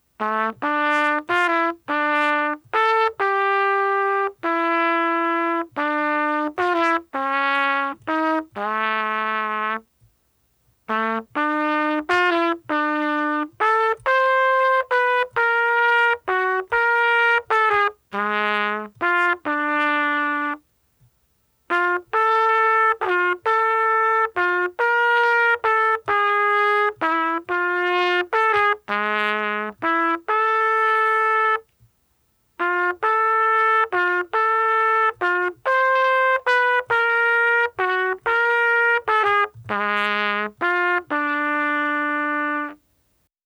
Some trumpet recordings, I'm getting better!